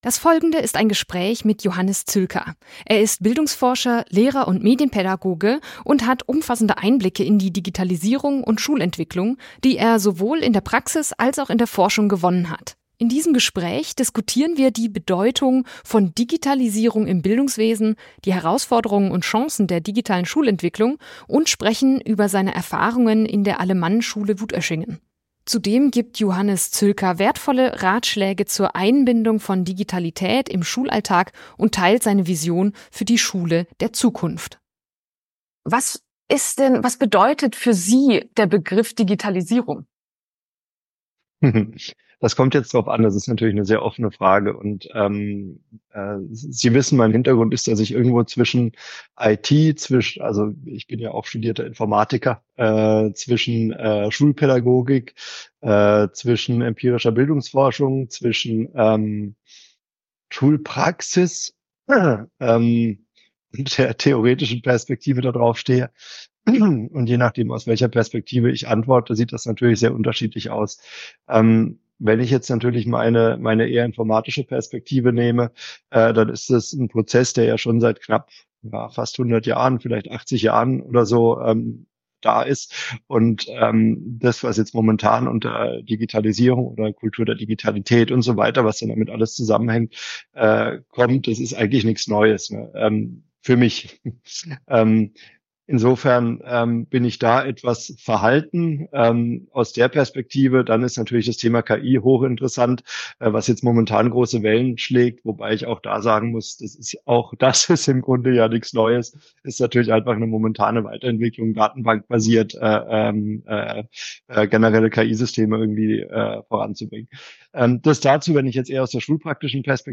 Schulentwicklung braucht Mut: Ein Gespräch